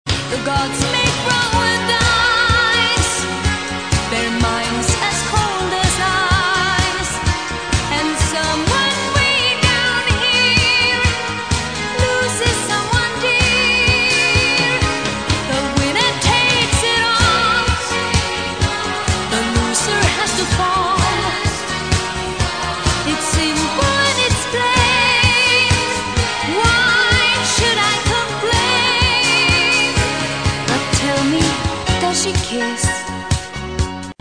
Главная » рингтоны на телефон » Ретро